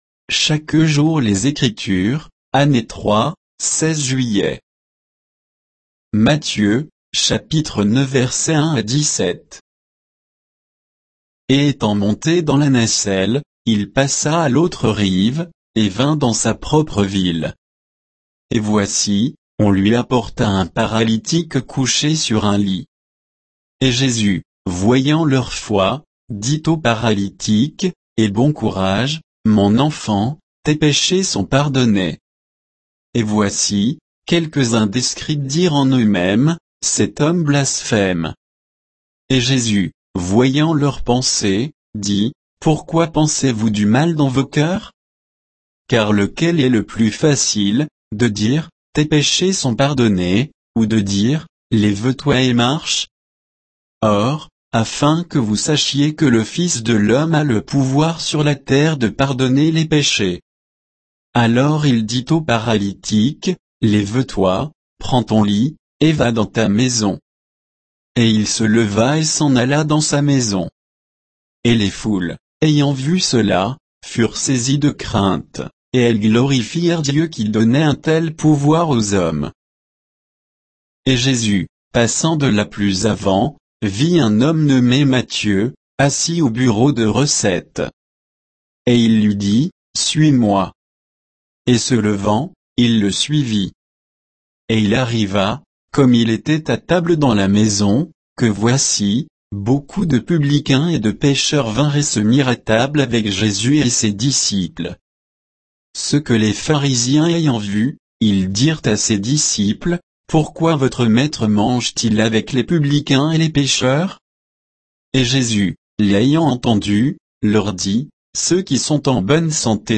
Méditation quoditienne de Chaque jour les Écritures sur Matthieu 9